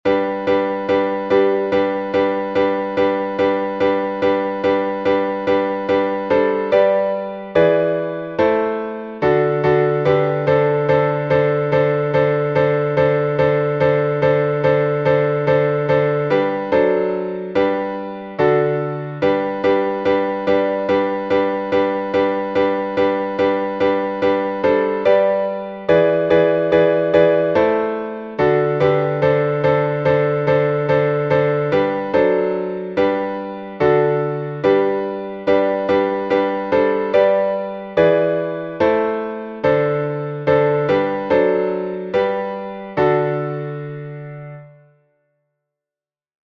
Сокращённый болгарский напев, глас 6